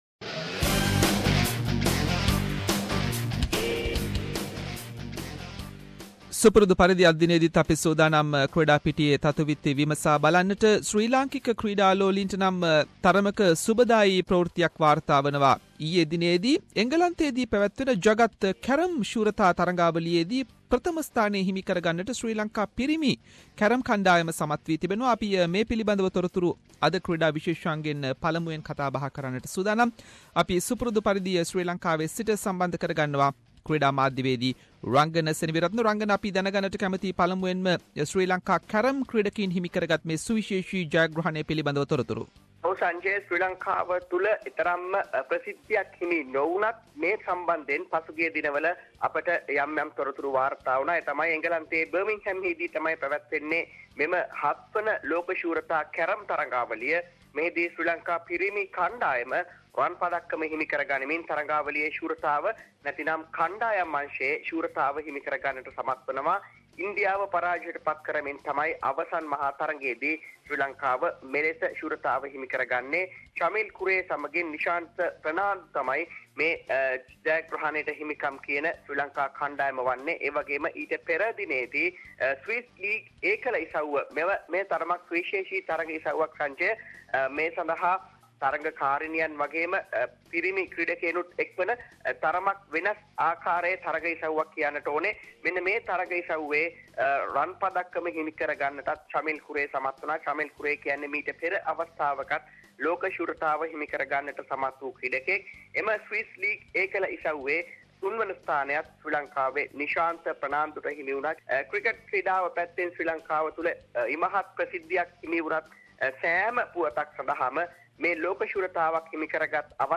sports wrap